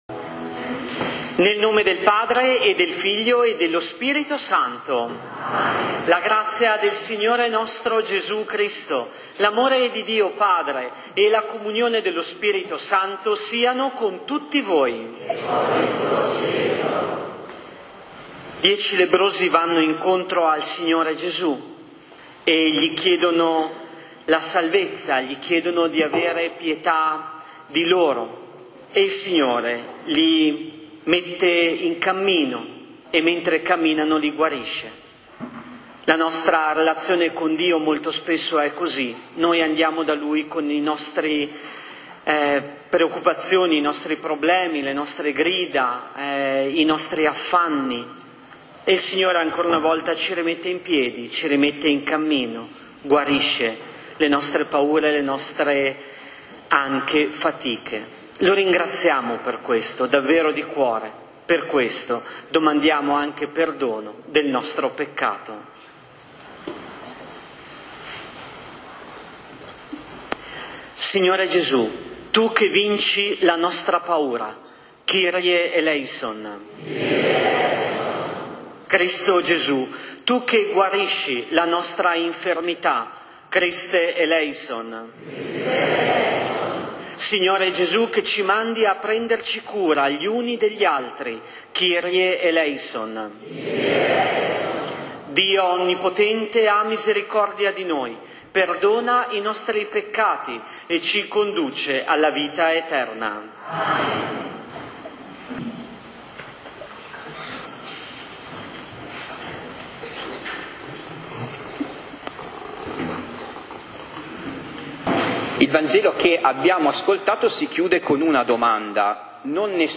Parrocchia San Giorgio Martire | Omelie della domenica: ARCHIVIO AUDIO | Omelie della domenica Anno Liturgico C 2024-2025